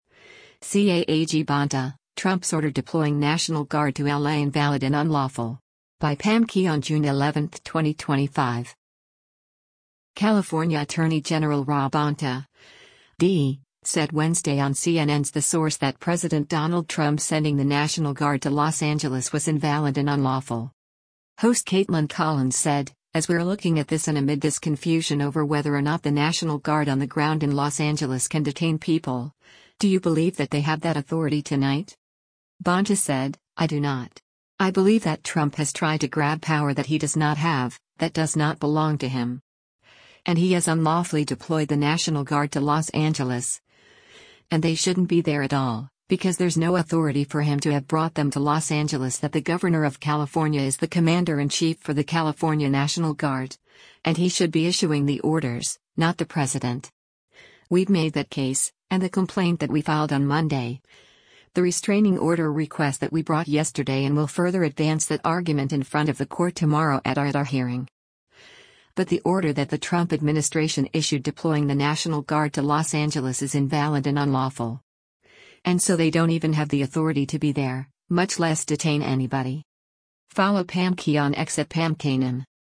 California Attorney General Rob Bonta (D) said Wednesday on CNN’s “The Source” that President Donald Trump sending the National Guard to Los Angeles was “invalid and unlawful.”